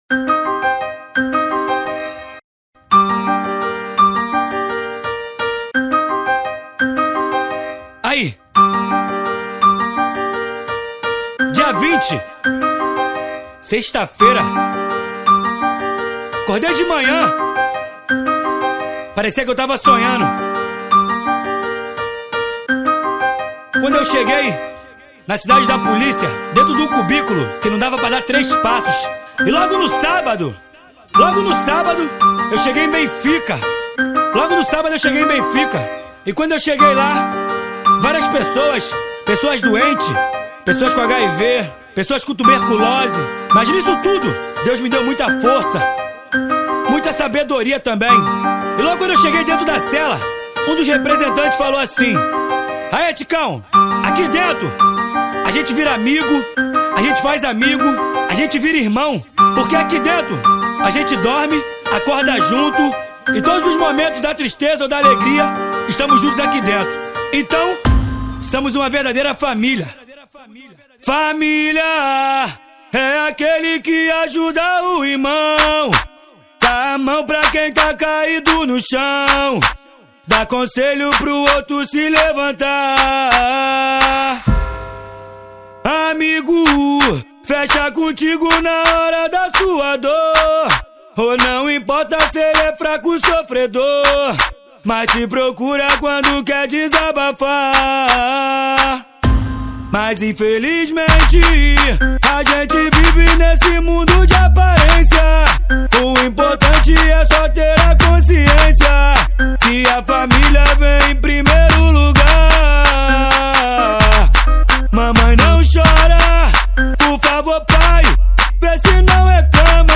2024-12-26 11:14:58 Gênero: Funk Views